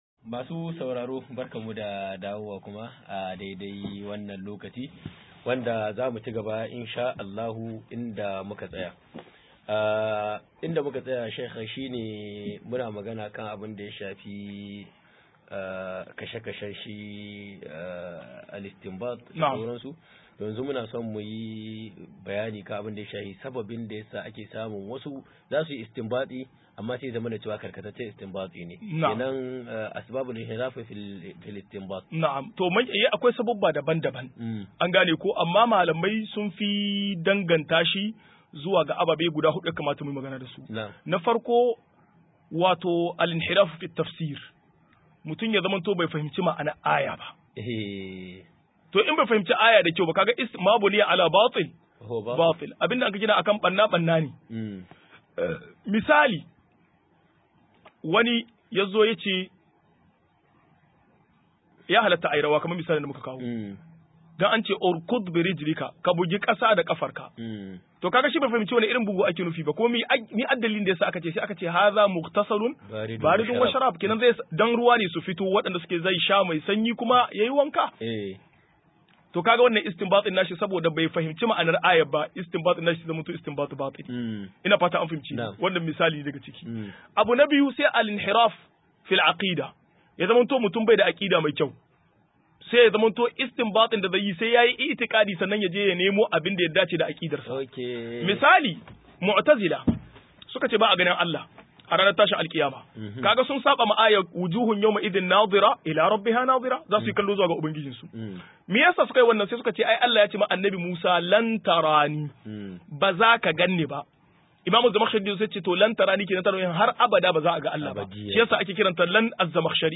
149-Bayani Kan Sababin Saukar Ayoyi - MUHADARA